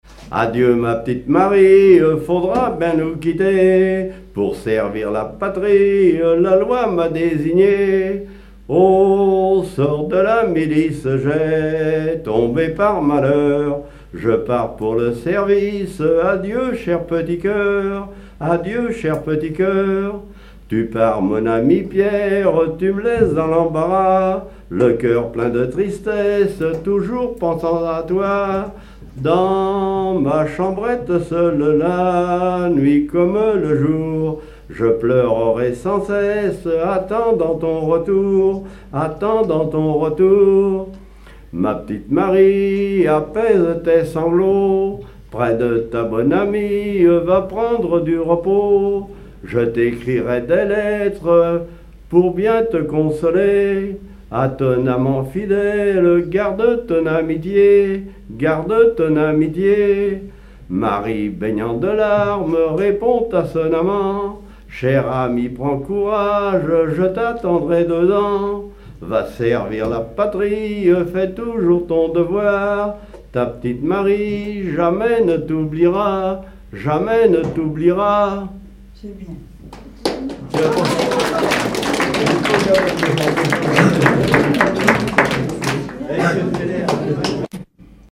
Genre strophique
Veillée (version Revox)
Pièce musicale inédite